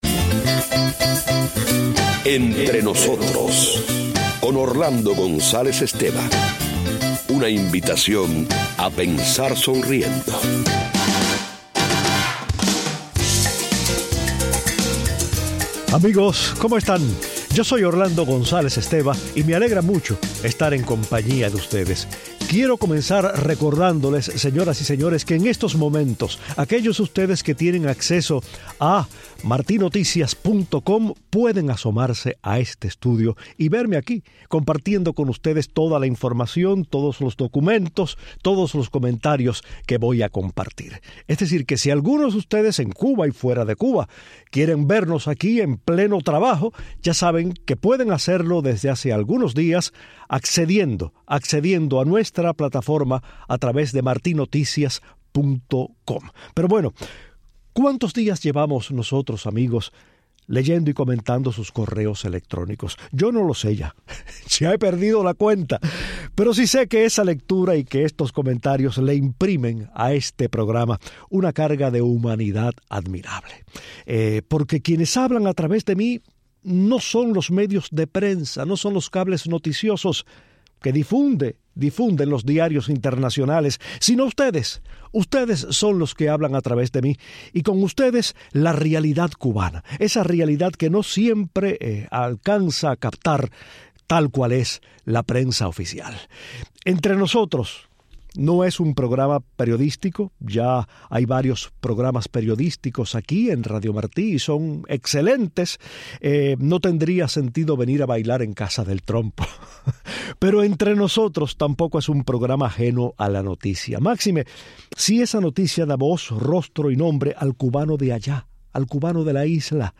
lee y comenta un nuevo grupo de correos electrónicos